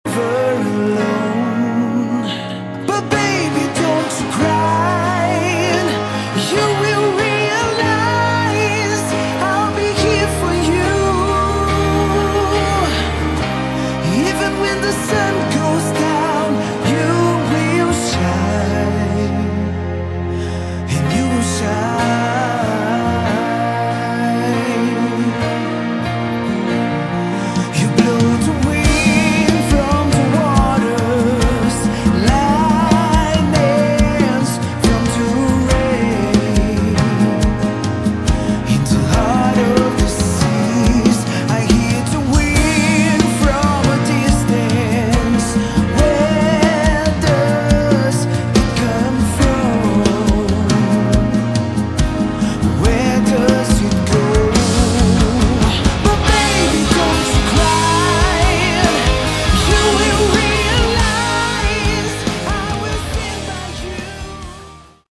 Category: Melodic Hard Rock
vocals
guitars
bass
drums